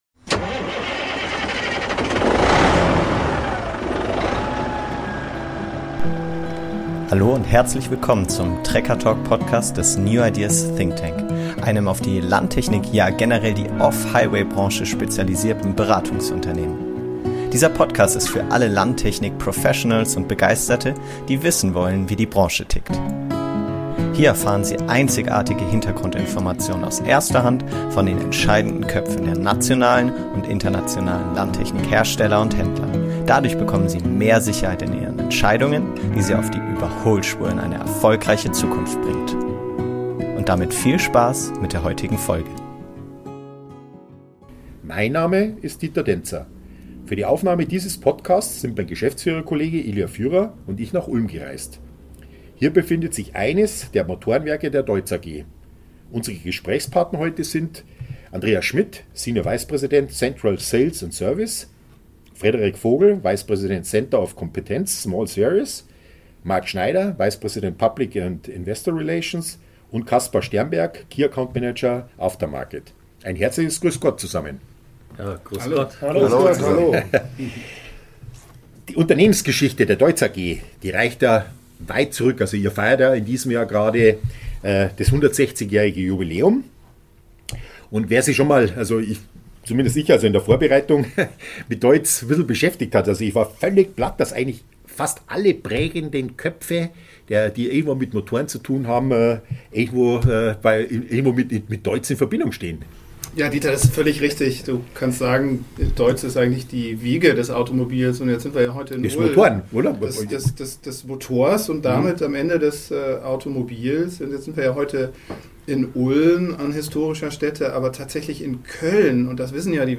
Natürlich wurde in der Talkrunde das wirtschaftliche Umfeld für das Unternehmen aber generell auch die Welt der Verbrennungsmotoren und Antriebsalternativen diskutiert.